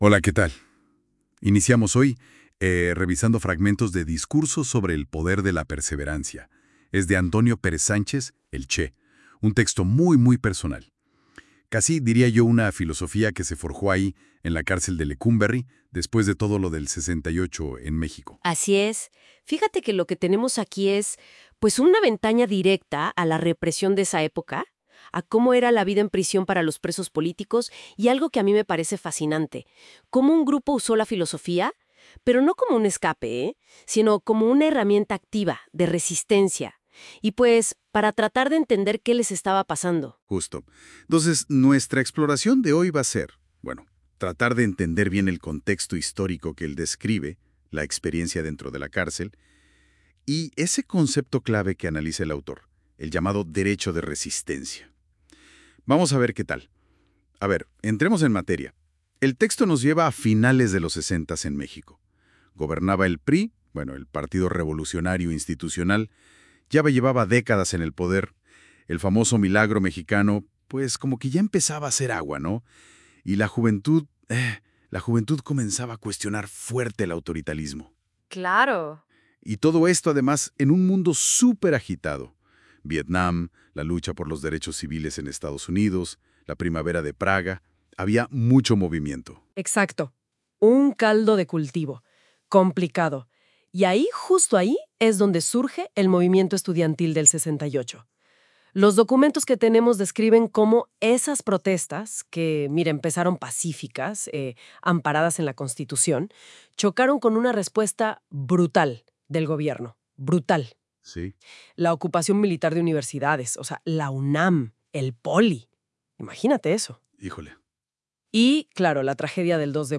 resena.mp3